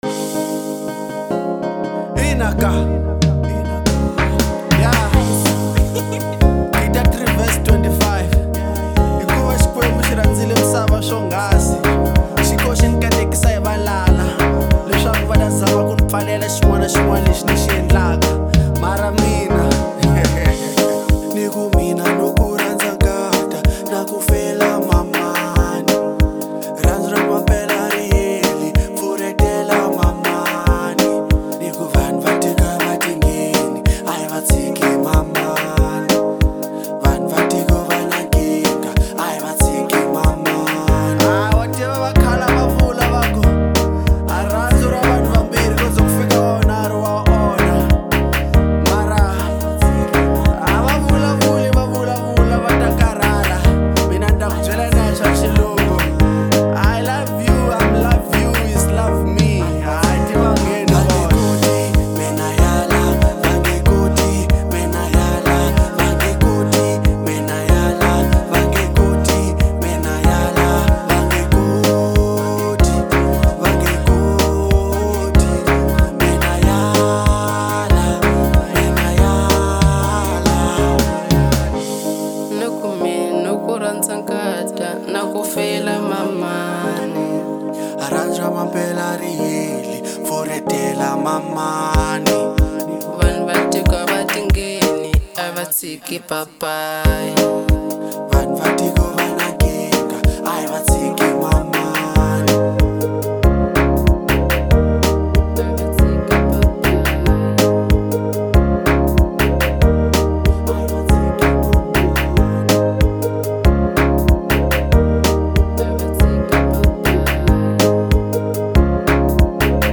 03:30 Genre : Marrabenta Size